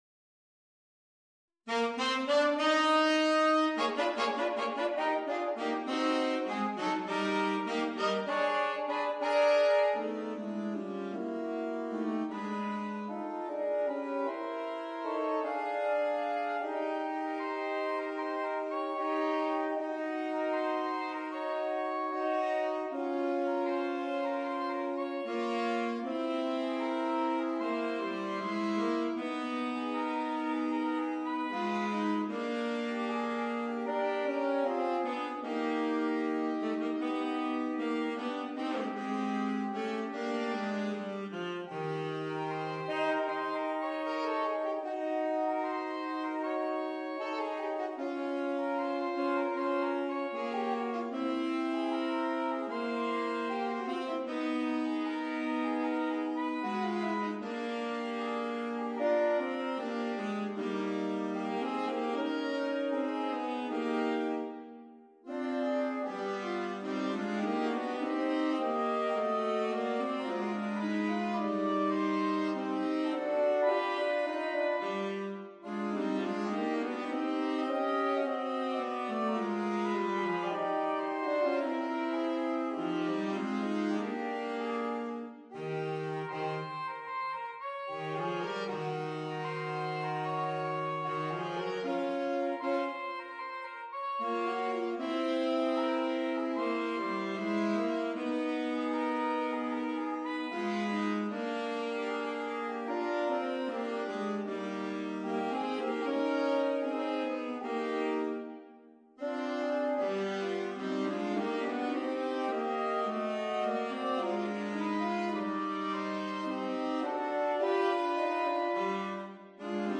für drei Saxophone (SAT)